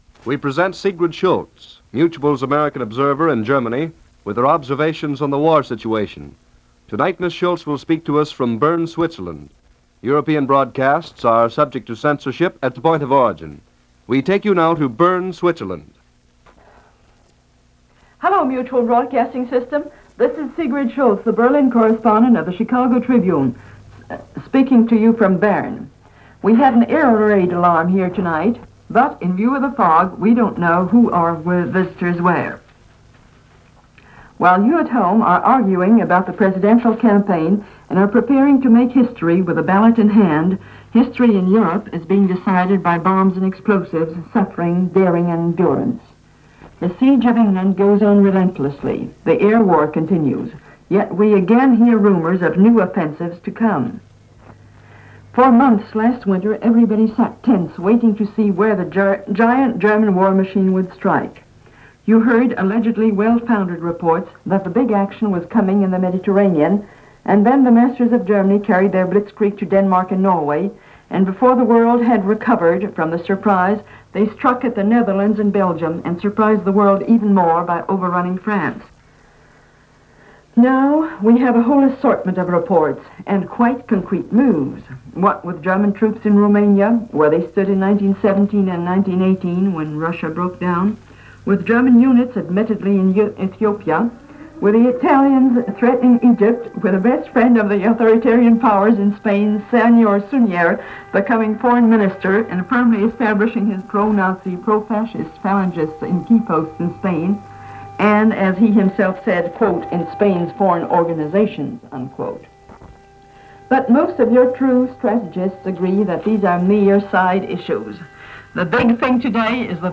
As from air raid sirens (one of which is heard during the broadcast), Switzerland seemed like one of the few safe spots in the world this day, certainly as far as Europe was concerned.